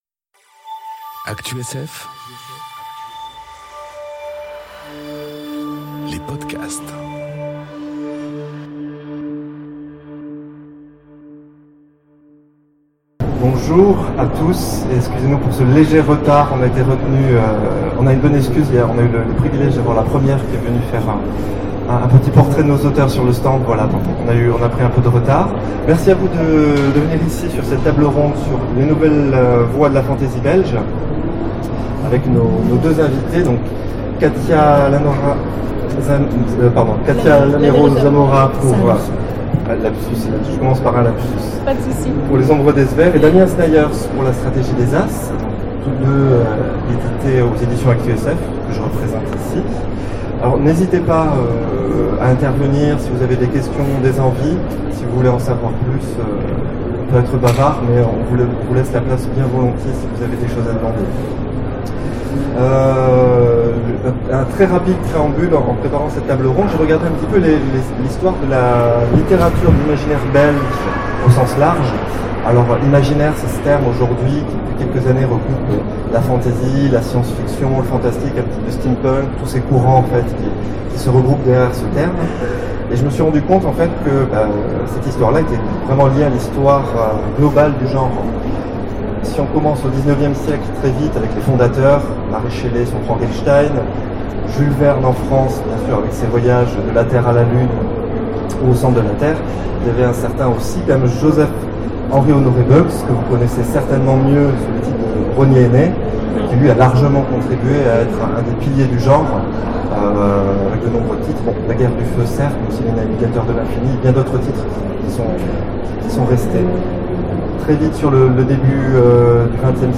Foire du livre de Bruxelles 2019 : Table ronde Les nouvelles voix de la fantasy belge
2019_foire_livre_bruxelles _ACTUSF_imaginaire_belge_ok.mp3